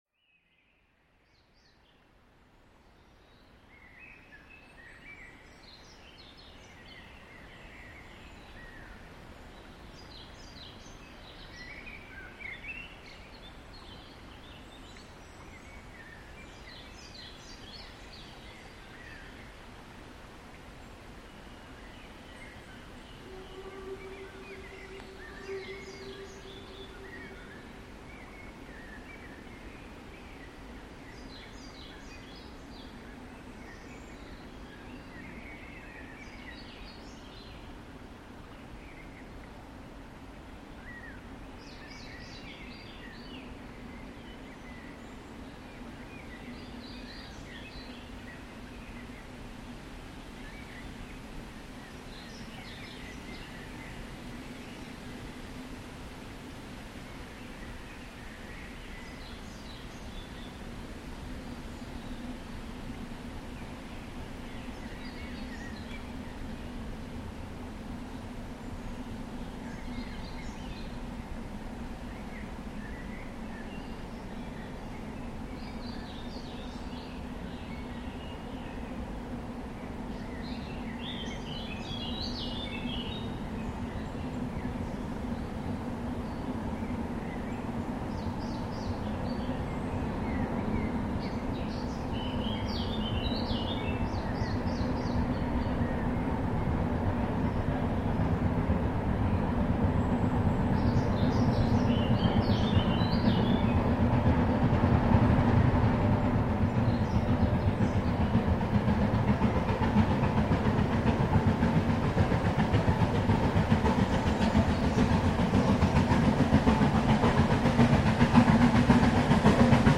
Stammlok für die Selketalbahn war nun nach (zeitweiser??) Abstellung der 6001 gerade ausschließlich die Neubaulok 7243. Von den starken Neubauloks überhaupt etwas Sound zu hören, funktioniert eigentlich nur an einem einzigen Ort, nämlich am unteren Ramberg nördlich von Mägdeprung, wenn... ein Zug auch mindestens aus 4 Wagen besteht.
99 7243 Tv mit Zug 8964 von Hasselfelde nach Quedlinburg, aufgenommen am unteren Ramberg vom Heinrichsburg-Berg von oben, um 16:45h am 28.05.2025.   Hier anhören: